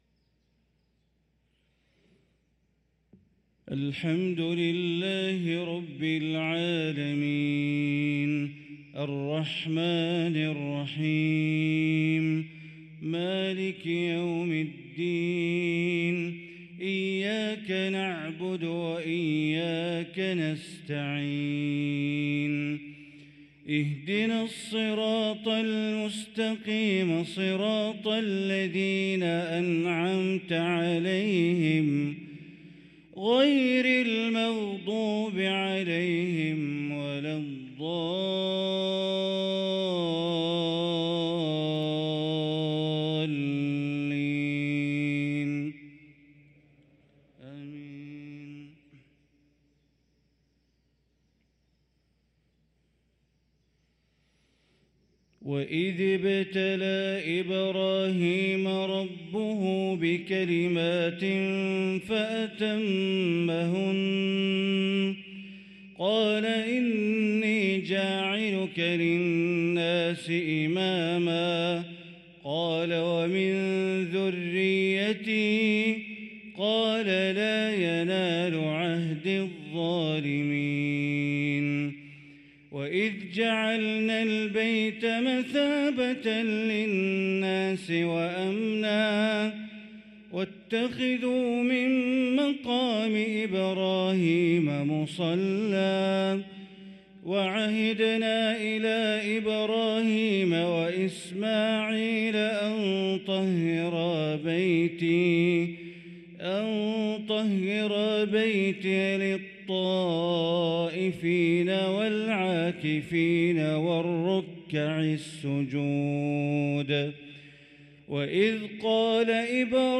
صلاة الفجر للقارئ بندر بليلة 19 ربيع الأول 1445 هـ
تِلَاوَات الْحَرَمَيْن .